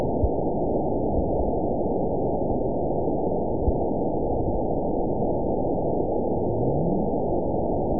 event 920414 date 03/23/24 time 22:12:08 GMT (1 year, 1 month ago) score 9.49 location TSS-AB04 detected by nrw target species NRW annotations +NRW Spectrogram: Frequency (kHz) vs. Time (s) audio not available .wav